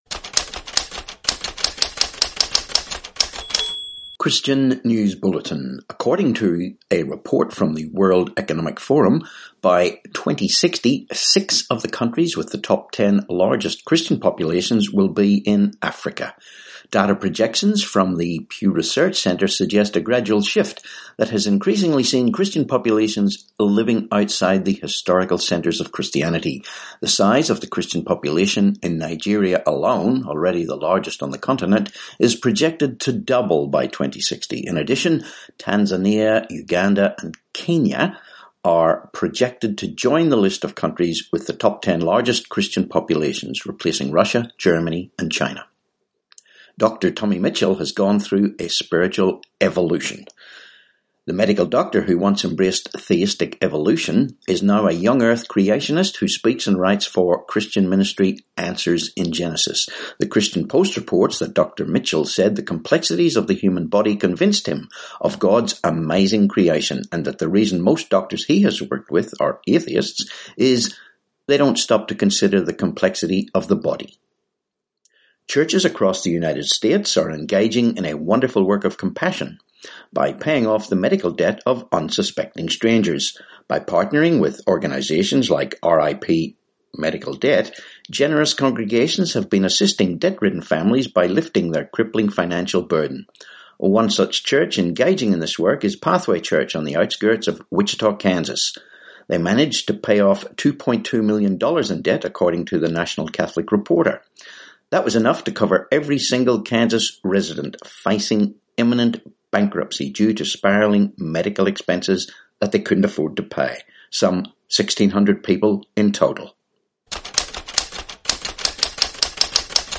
4Aug19 Christian News Bulletin